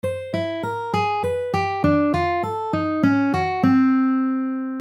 The crab means nothing more than that the line you just played is played again, but this time starting from the end.
12-Ton-Line-Krebs.mp3